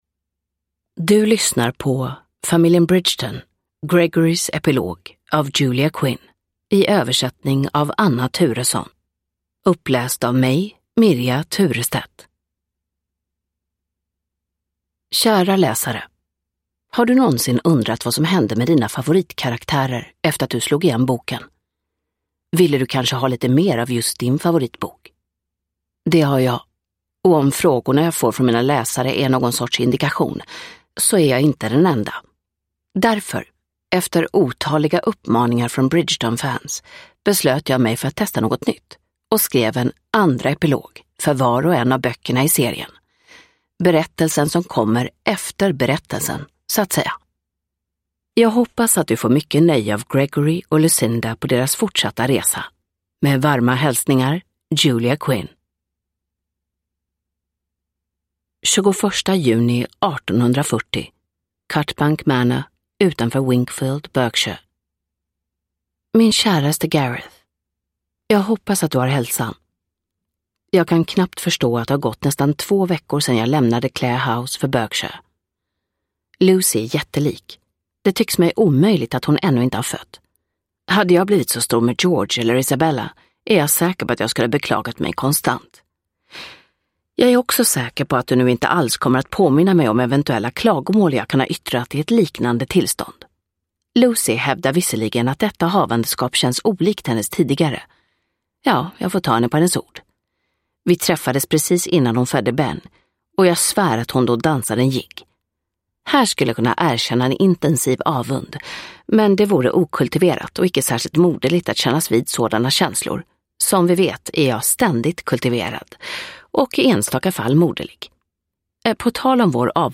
Gregorys epilog – Ljudbok – Laddas ner